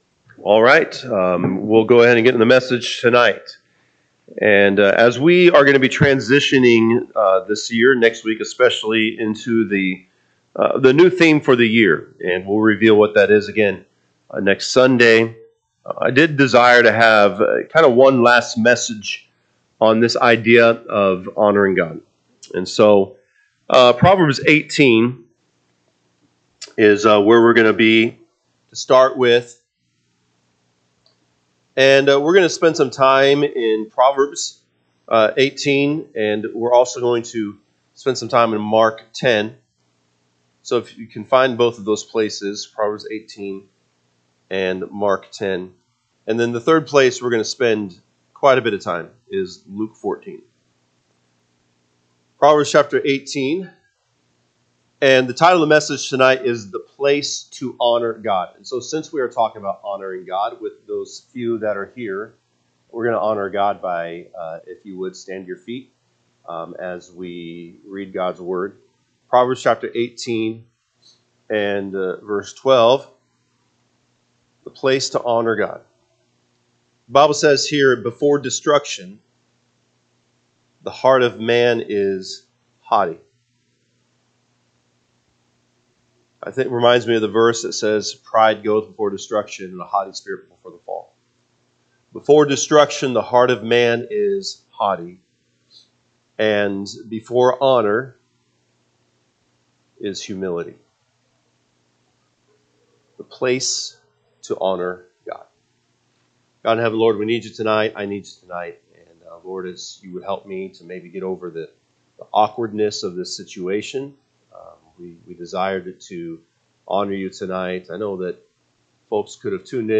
Sunday PM Message